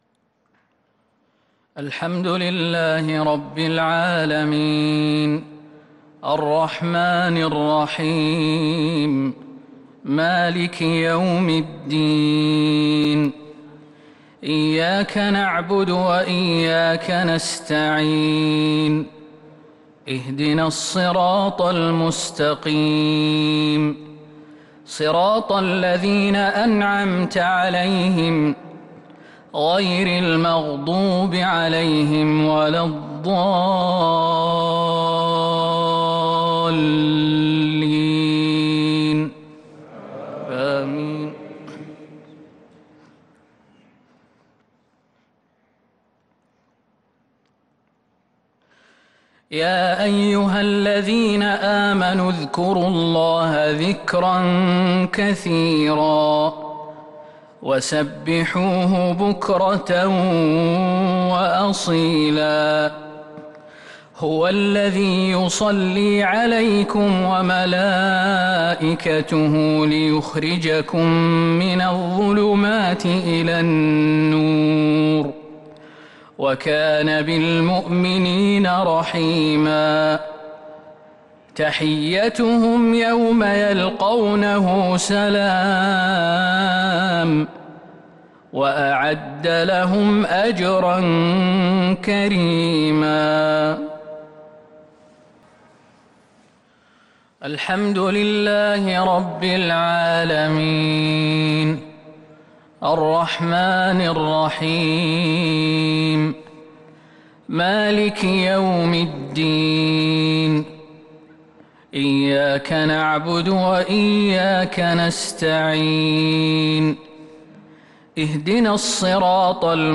صلاة المغرب للقارئ خالد المهنا 8 ربيع الأول 1443 هـ
تِلَاوَات الْحَرَمَيْن .